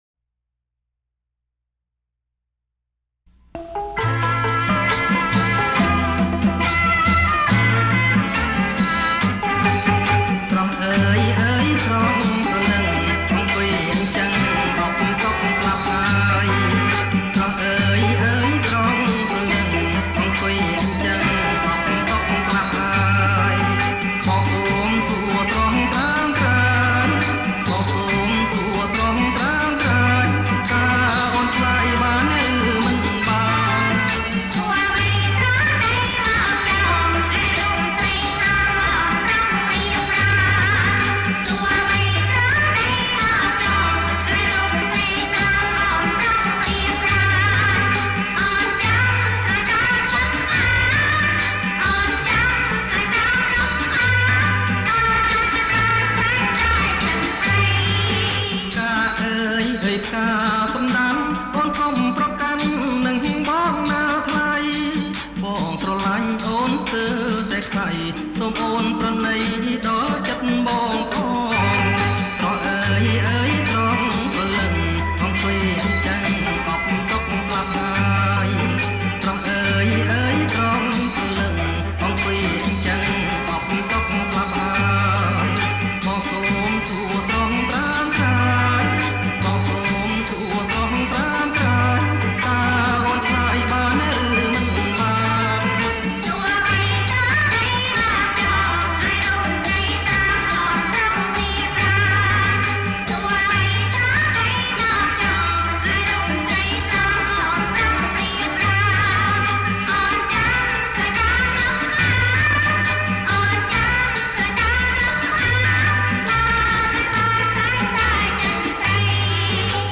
• ប្រគំជាចង្វាក់ រាំវង់